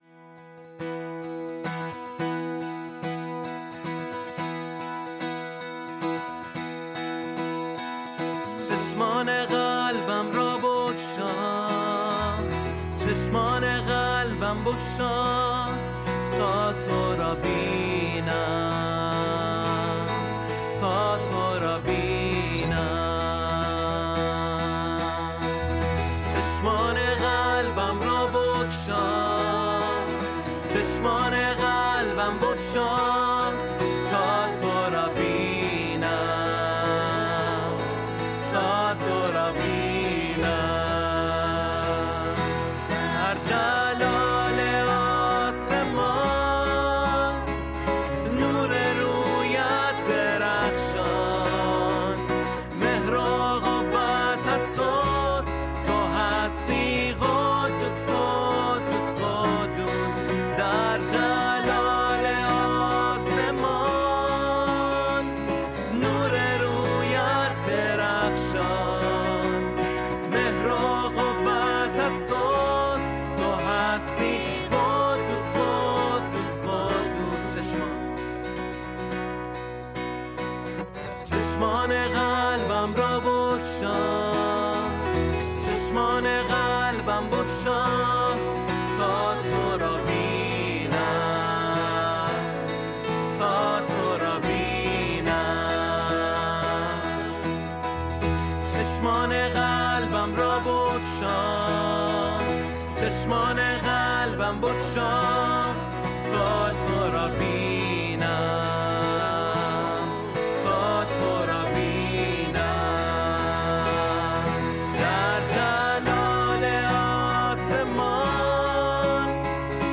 ماژور